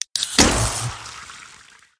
snd_drink.ogg